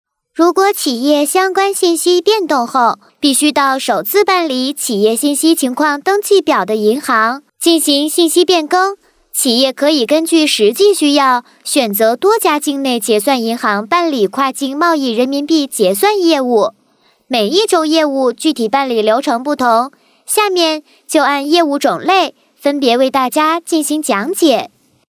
女国81_动画_童声_女童银行业务.mp3